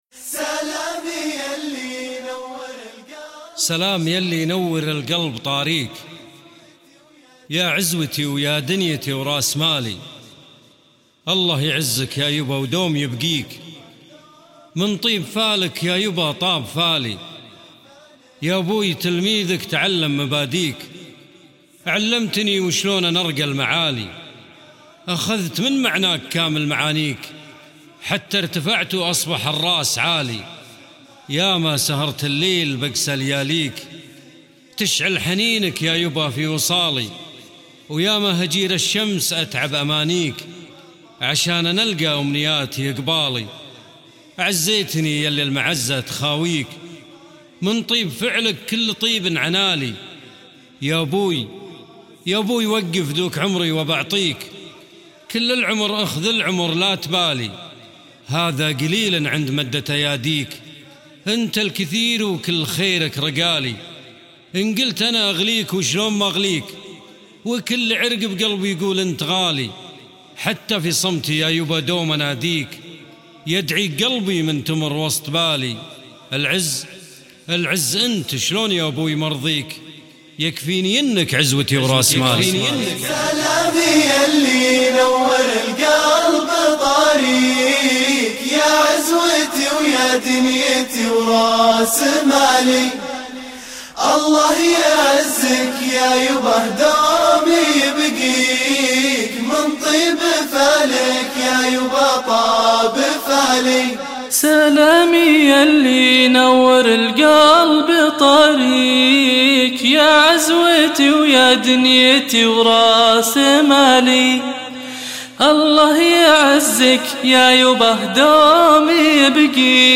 انشودة